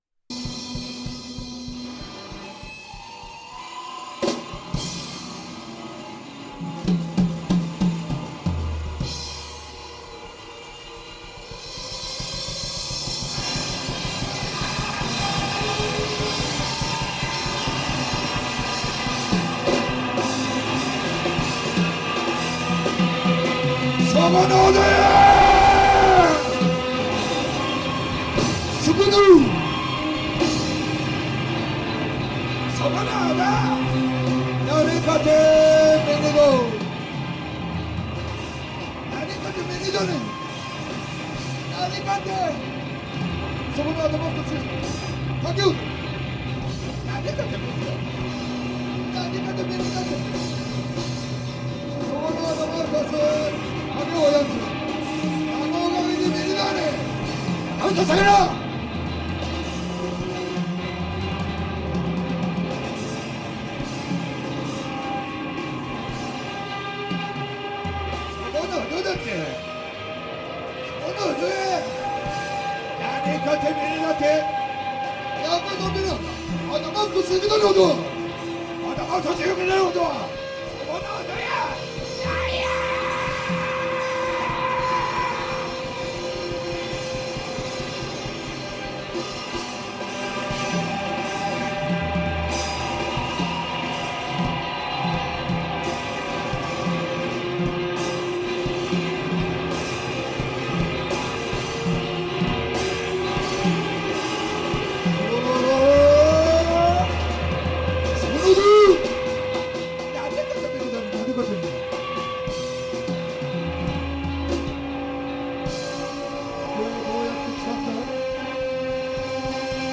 Japanese psychedelic rock supergroup